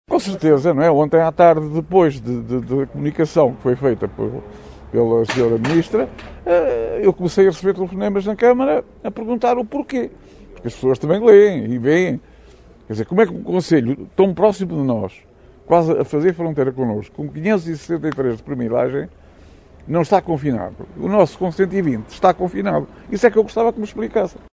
Rogério Abrantes presidente da CM de Carregal do Sal
Em declarações aos jornalistas, Rogério Abrantes defende a revisão dos critérios e não esconde a revolta.